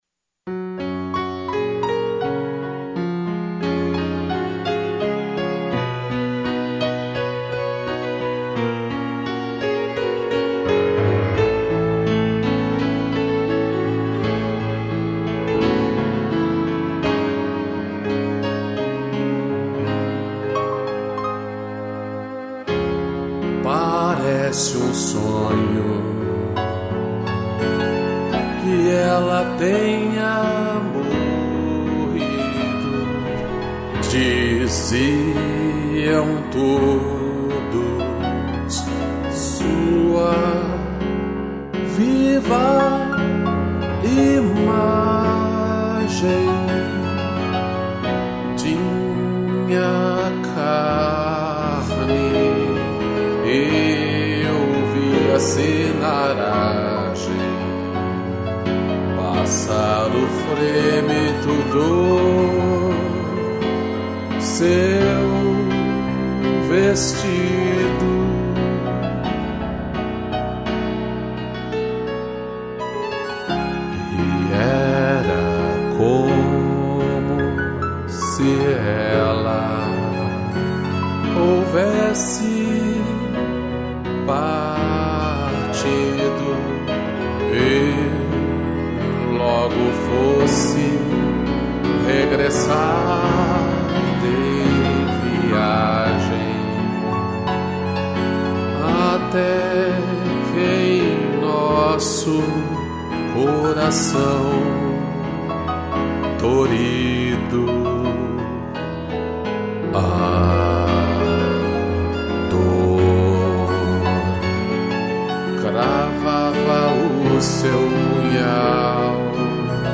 2 pianos, cello, violino